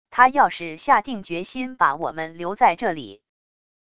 他要是下定决心把我们留在这里wav音频生成系统WAV Audio Player